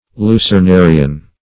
Search Result for " lucernarian" : The Collaborative International Dictionary of English v.0.48: Lucernarian \Lu`cer*na"ri*an\, a. (Zool.) Of or pertaining to the Lucernarida.